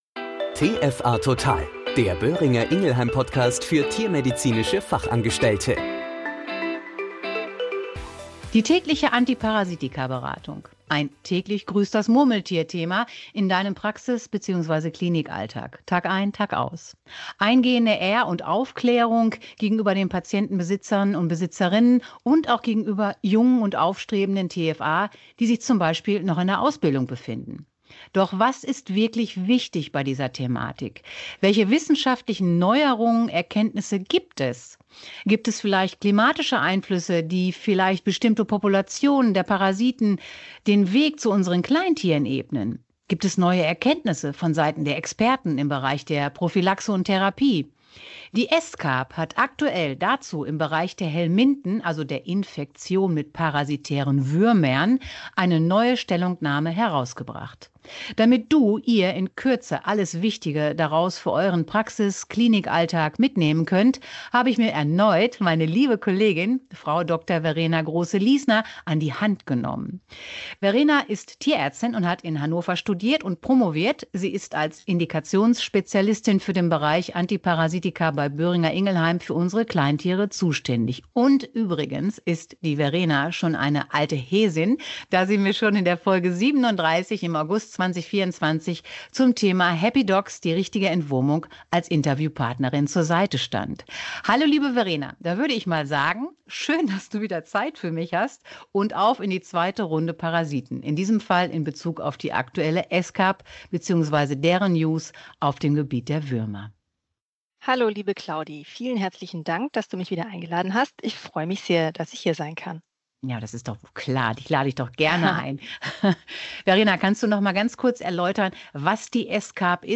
Die Parasitologen der ESCCAP haben eine neue Empfehlung zum Thema “Würmer“ bei Hund & Katze herausgegeben und diese wird in diesem Interview eingehend beleuchtet!